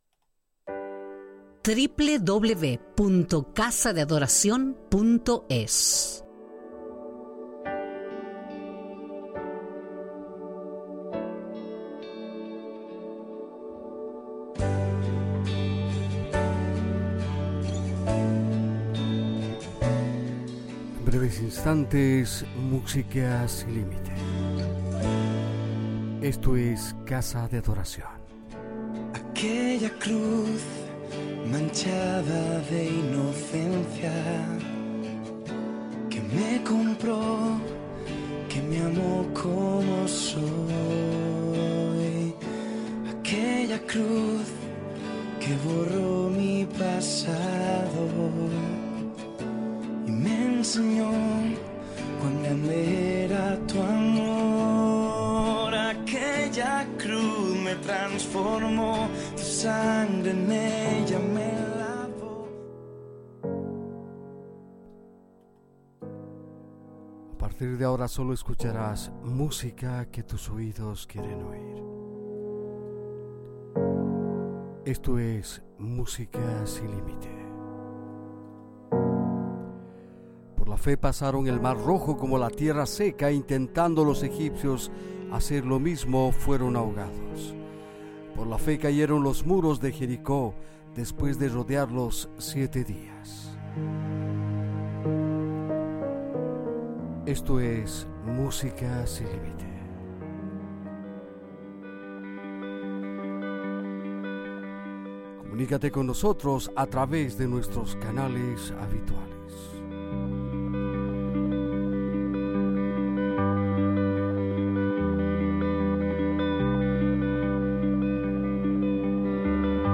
Adreça web, presentació, tema musical
Musical